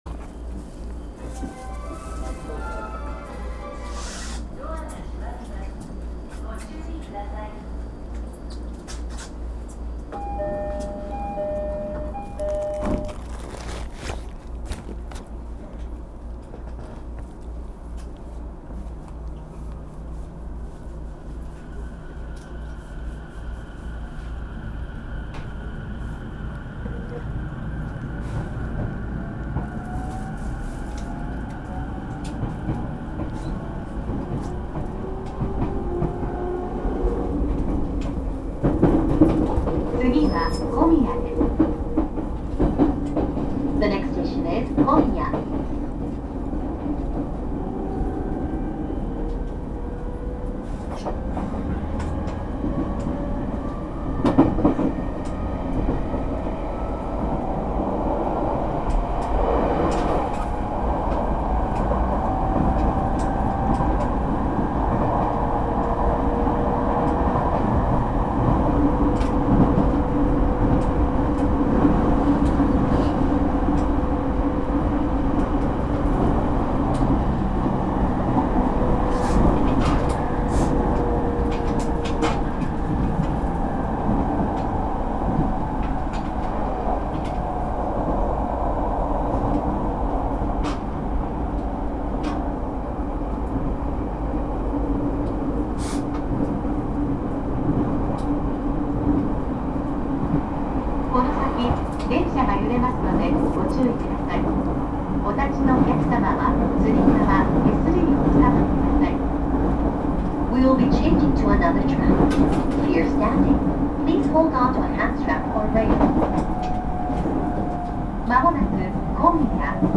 走行音
録音区間：北八王子～小宮(お持ち帰り)